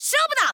File:Pit voice sample JP.oga
Pit_voice_sample_JP.oga.mp3